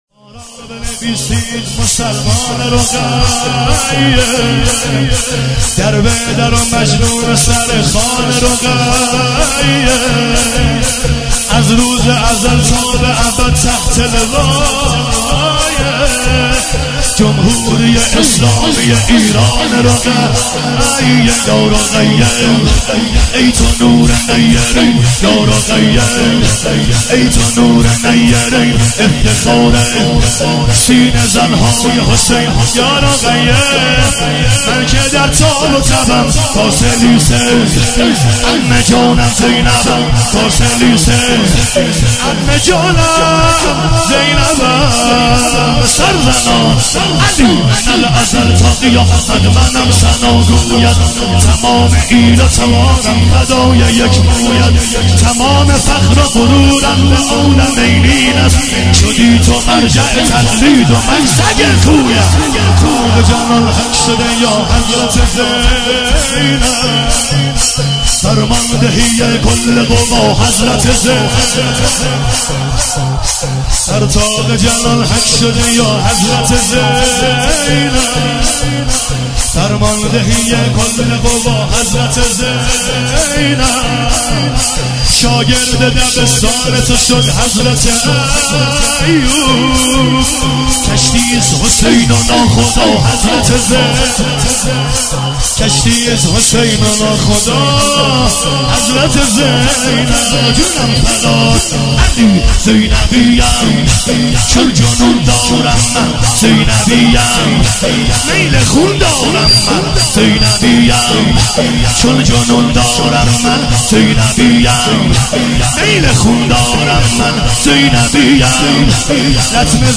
هیئت جانثاران امام زمان (عج)